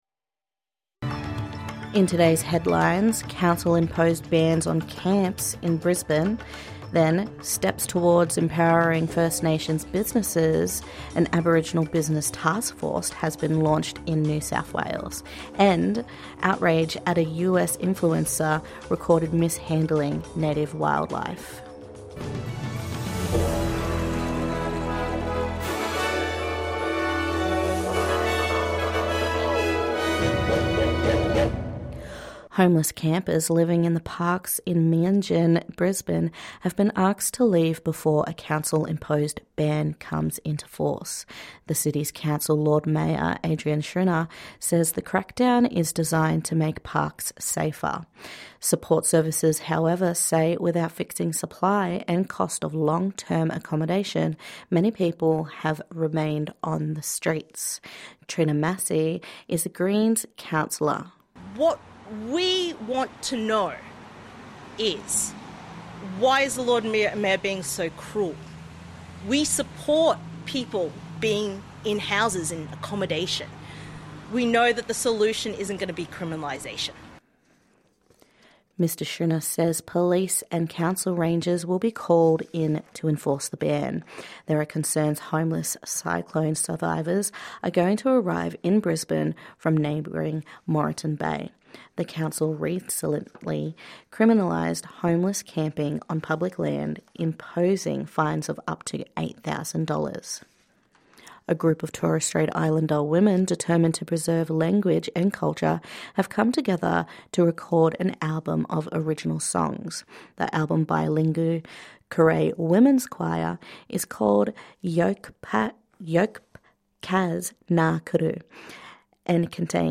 That and more on NITV Radio.